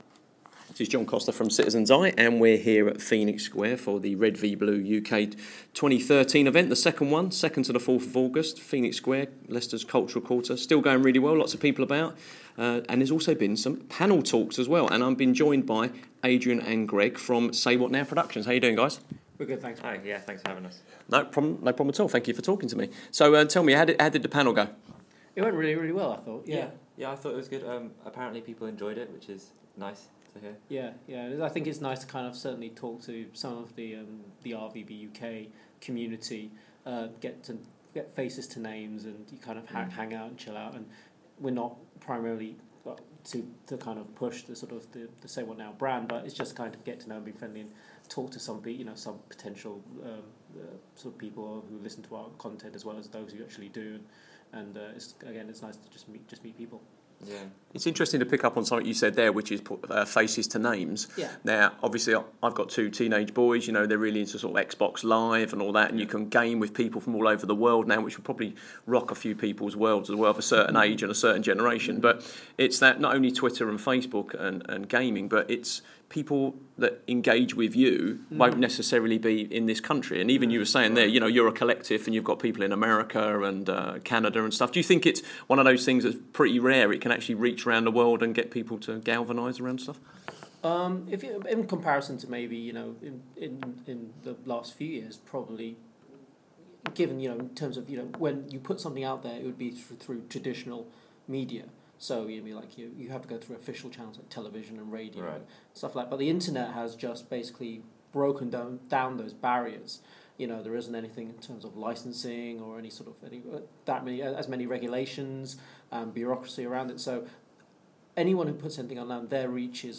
Interview
at the R v B : UK 2013 event at Phoenix Square